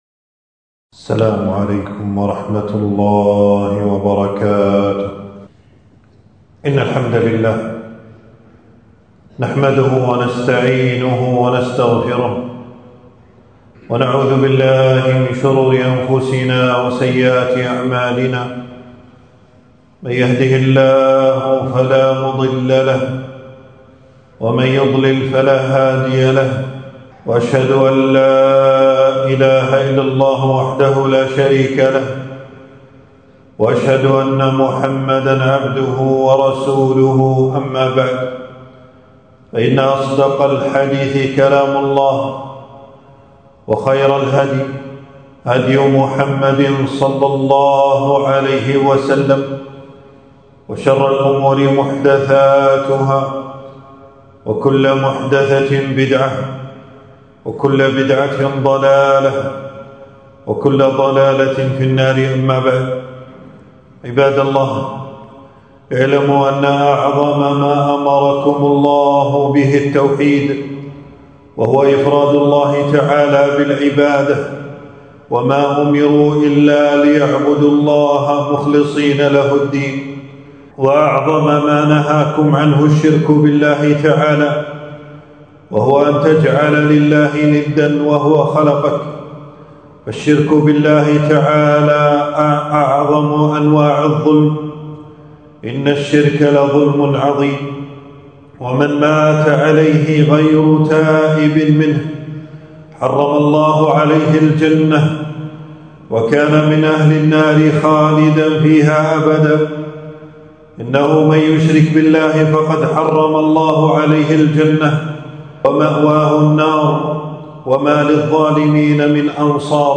تنزيل تنزيل التفريغ خطبة بعنوان: أهمية التوحيد وخطورة الشرك ووسائله.
المكان: في مسجد - أبو سلمة بن عبدالرحمن 22 شوال 1447هـ (بمدينة المطلاع).